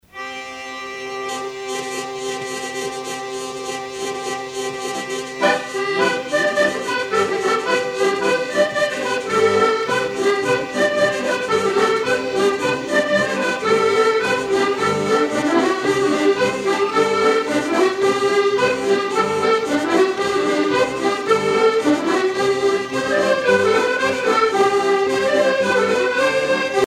danse : rondeau
Pièce musicale éditée